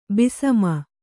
♪ bisama